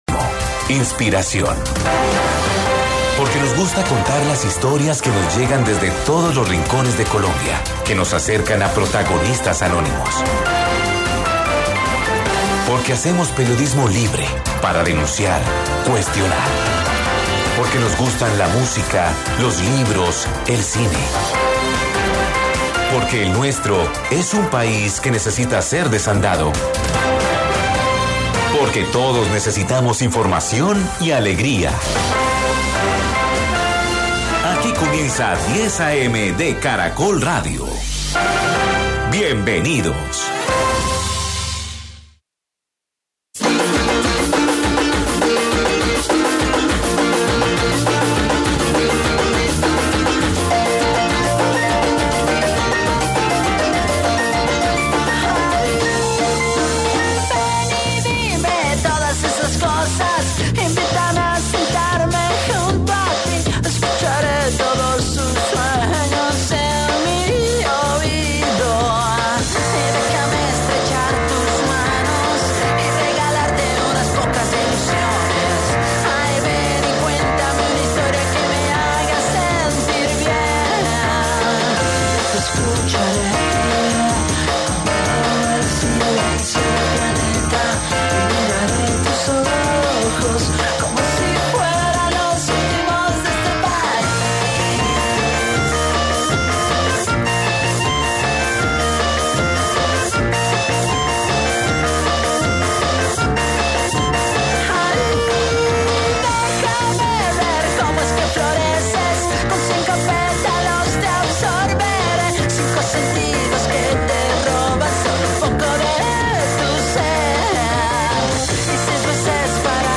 En su paso por el programa 10 AM de Caracol Radio, el candidato contó lo que se concretó en la consulta del 19 de julio, los posibles candidatos y el propósito del Frente Amplio para el Pacto Histórico.